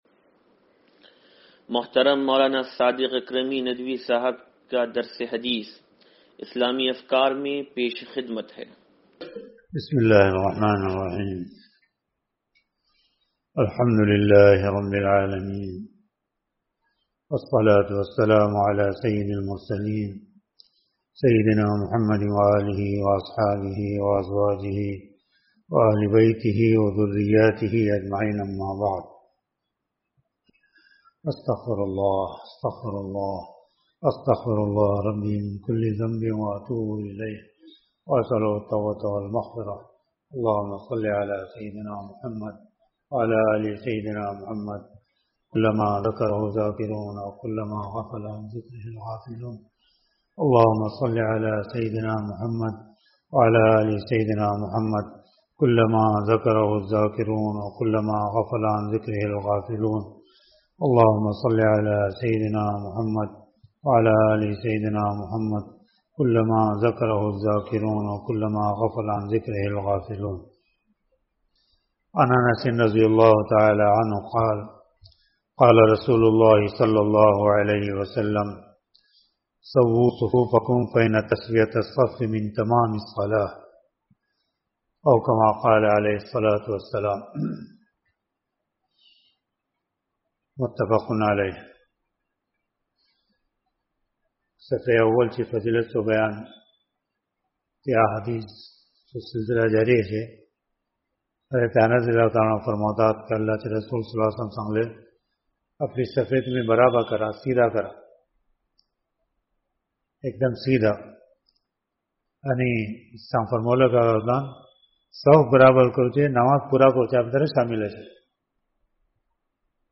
درس حدیث نمبر 0823